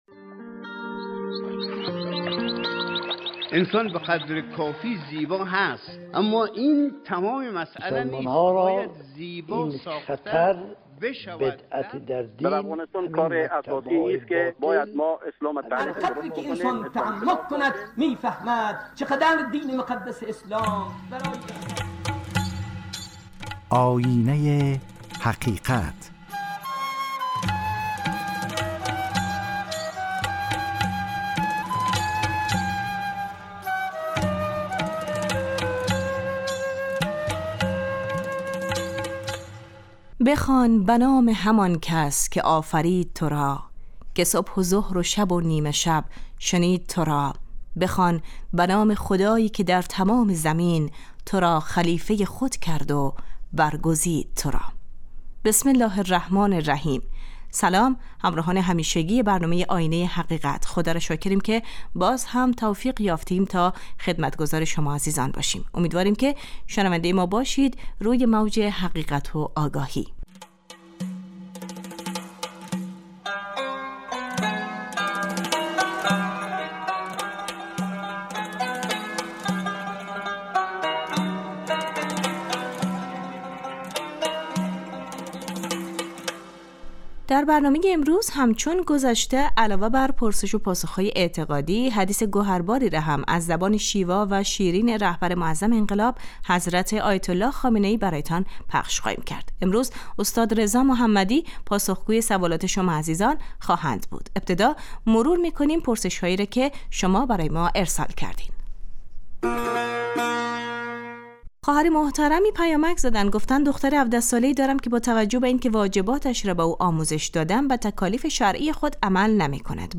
شرح حدیثی نیز با صدای رهبر معظم انقلاب حضرت آیت الله خامنه ای زینت بخش برنامه خواهد بود .
پرسش و پاسخ های اعتقادی